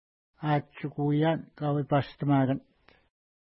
Atshikuian kauepashtamakanit Next name Previous name Image Not Available ID: 375 Longitude: -60.8923 Latitude: 54.0433 Pronunciation: a:tʃukuja:n ka:wepa:stəma:kant Translation: Where a Sealskin Was Let Loose in the Wind Feature: lake